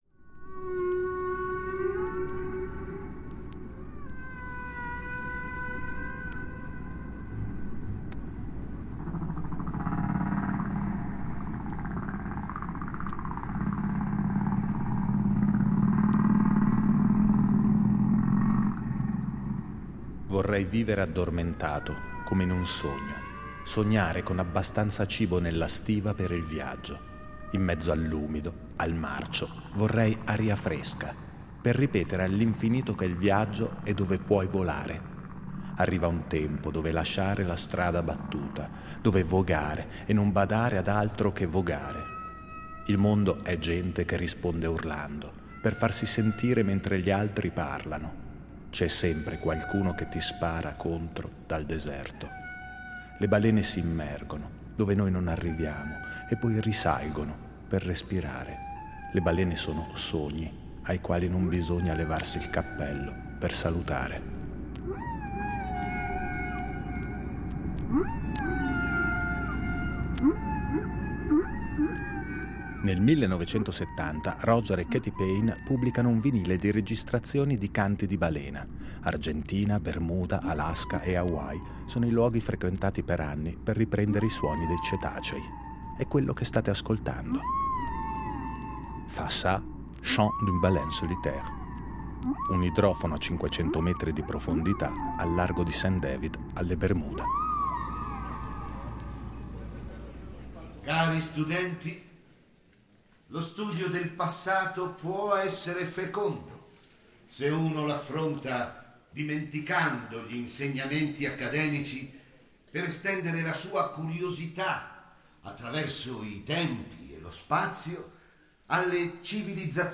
Radiodrammi